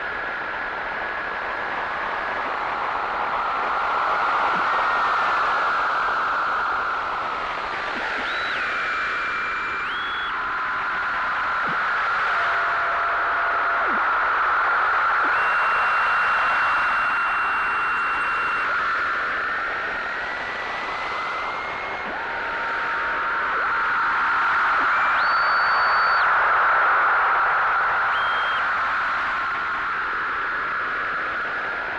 收音机播报
描述：Ghetto Blaster收音机播报天气和新闻报道。
标签： 无线电 静电 噪声 白色 外语
声道立体声